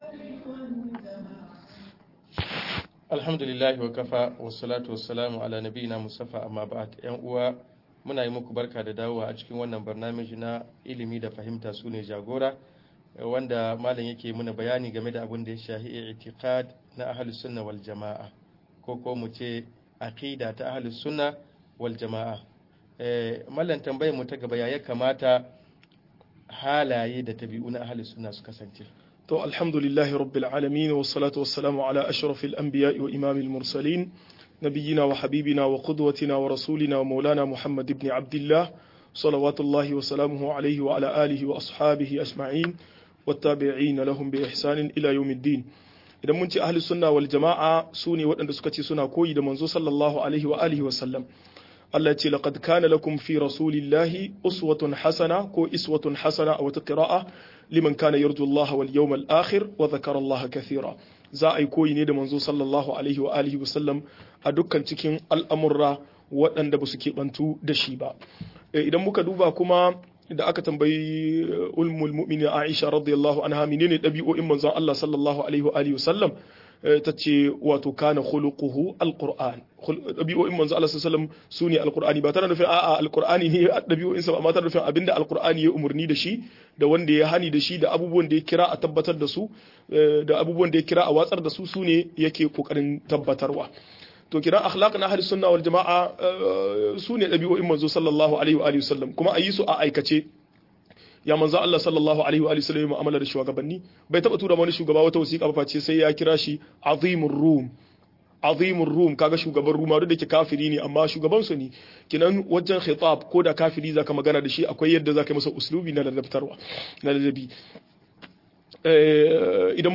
Tarihin aikin hajji - MUHADARA